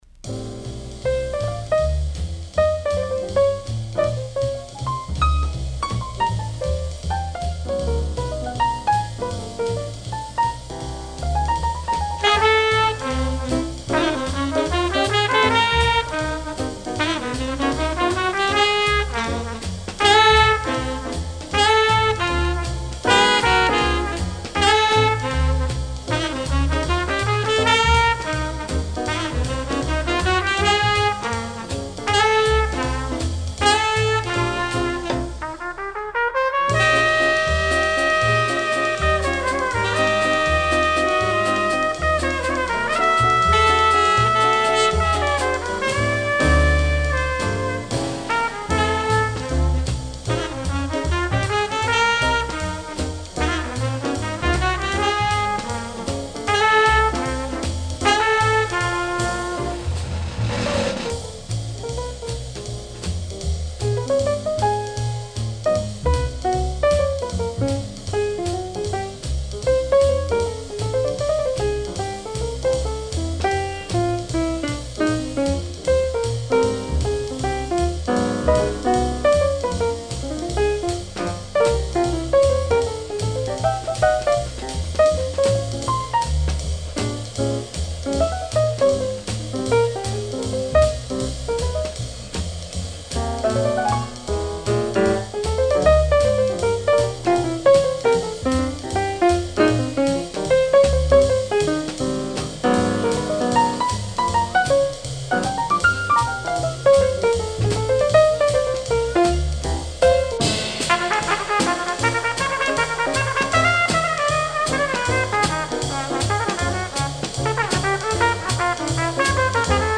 Original 1957 mono pressing